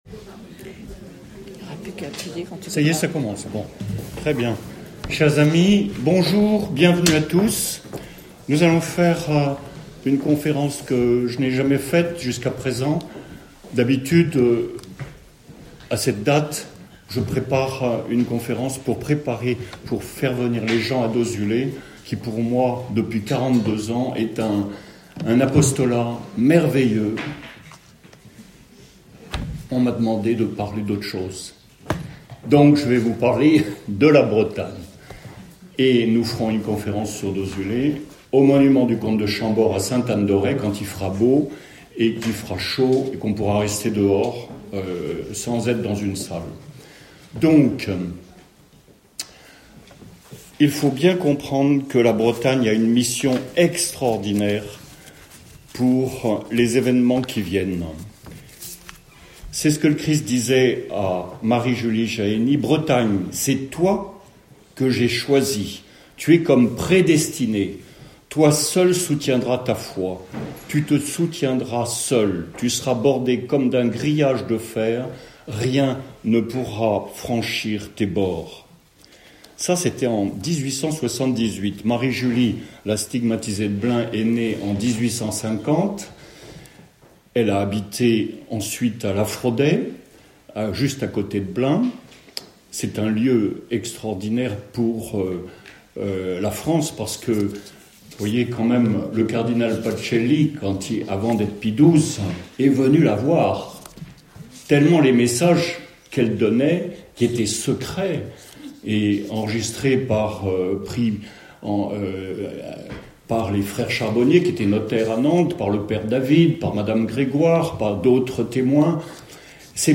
CONFÉRENCE Le 28 mars à Dozulé, à l’auberge Normande Grand-rue à 17 heures : Dernières nouvelles sur Dozulé. Ci-dessous l’audio de la conférence à Vannes le 15 février : La MIssion Extraordinaire de la Bretagne